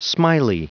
Prononciation du mot smiley en anglais (fichier audio)
Prononciation du mot : smiley